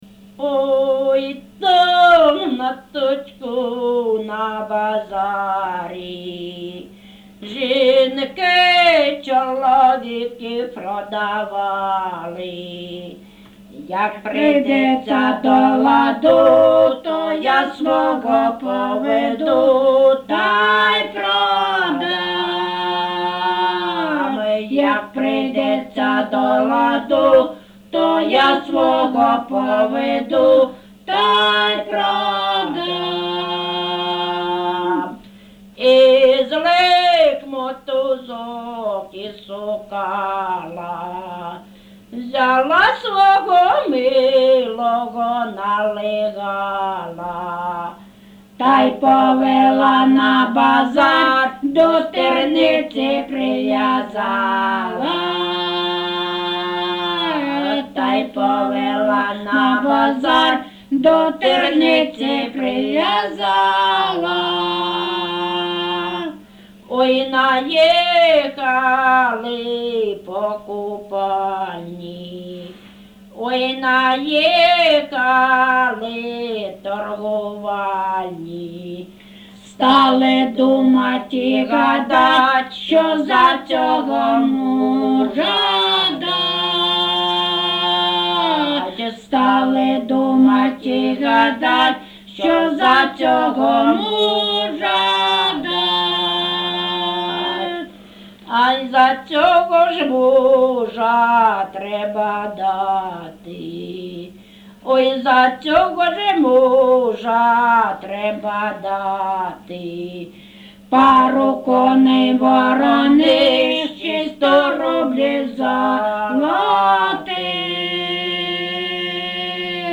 ЖанрЖартівливі
Місце записум. Старобільськ, Старобільський район, Луганська обл., Україна, Слобожанщина